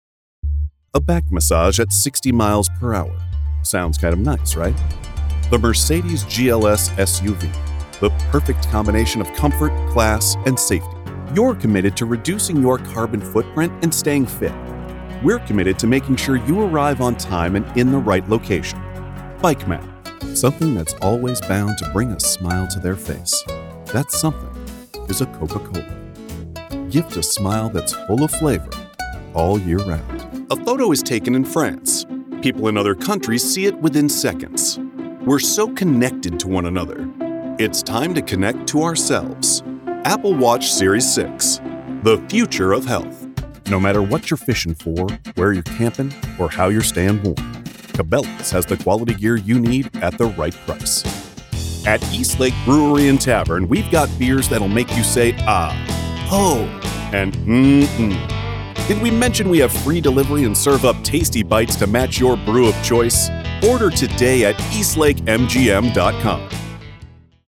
Microphone: Sennheiser MK4
All recordings performed in Whisper Booth
Commercial Demo